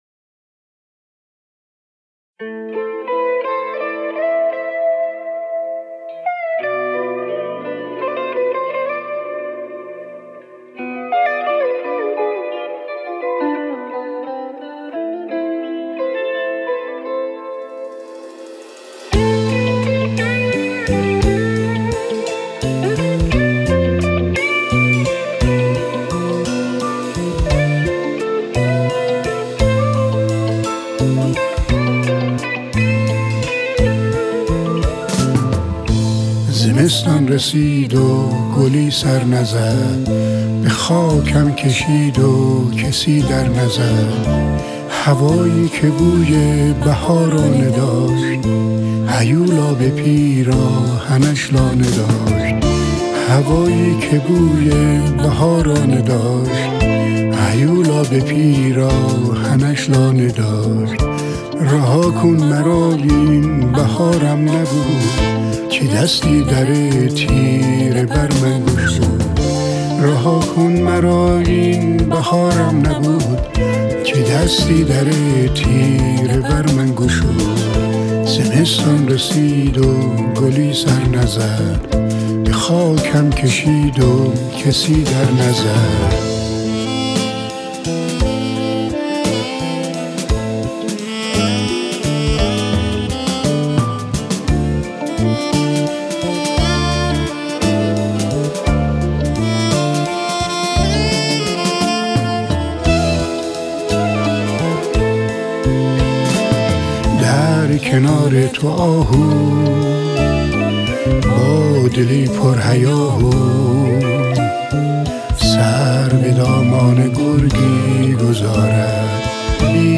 بک وکال